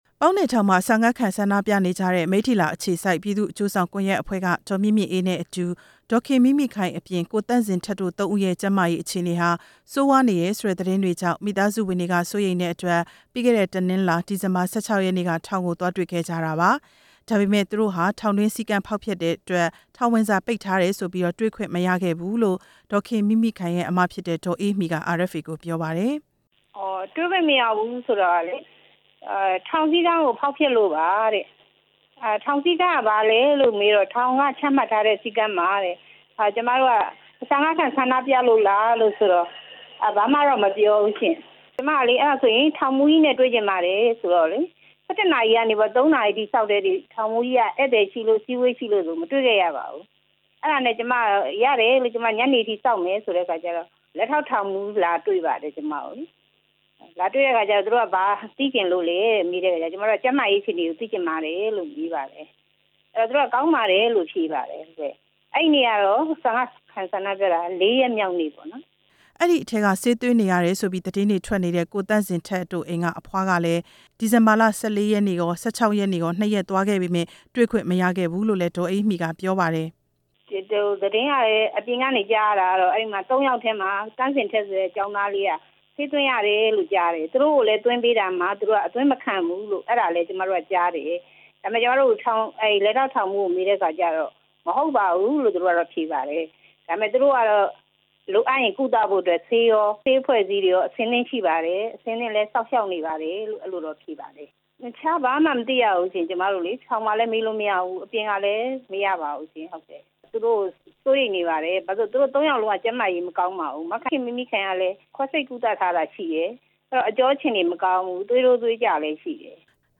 မိသားစုဝင်တွေနဲ့ ဆက်သွယ်မေးမြန်းချက်